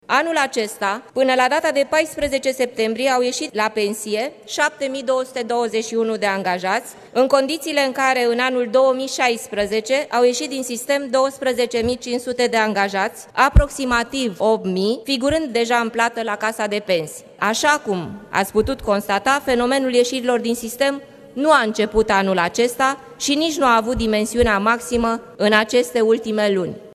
Chemată luni în Parlament să vorbească, în calitate de ministru de Interne, despre lipsa personalului din poliție, Carmen Dan a respins ideea că ieșirile la pensie ar fi fost în vreun fel încurajate de deciziile politice din acest an.